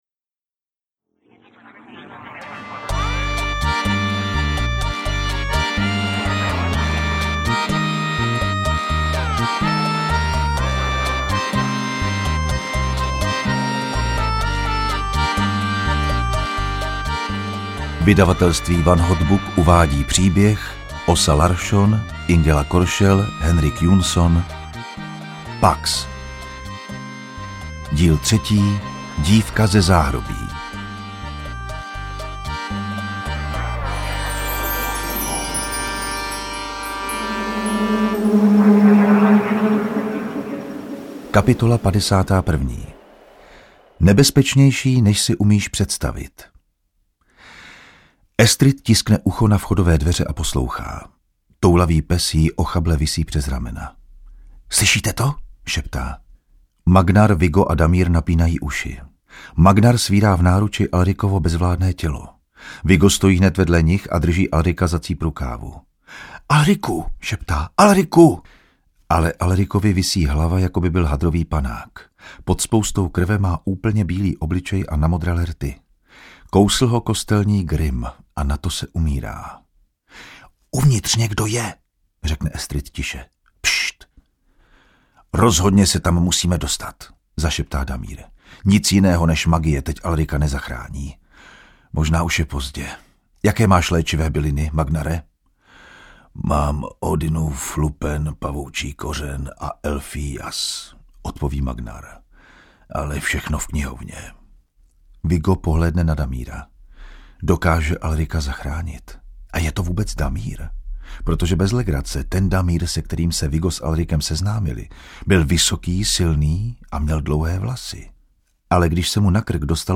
Interpret:  Ondřej Vondráček
AudioKniha ke stažení, 77 x mp3, délka 5 hod. 49 min., velikost 317,0 MB, česky